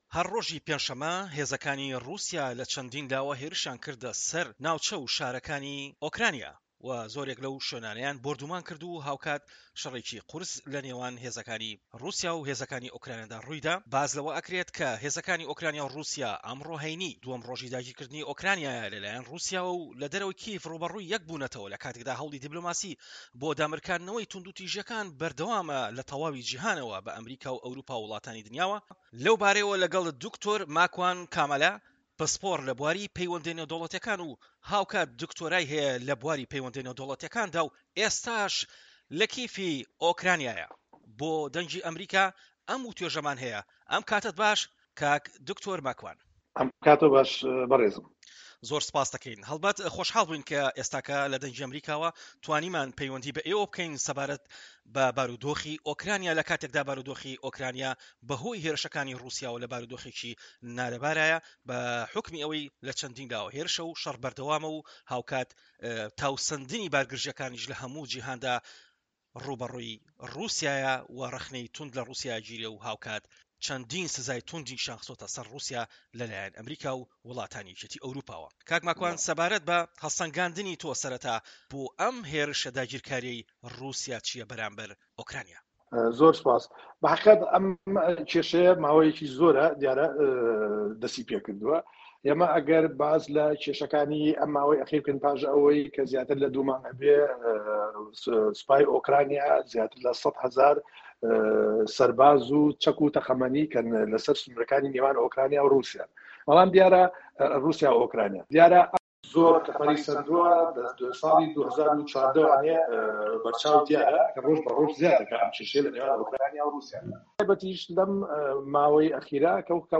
وتوێژی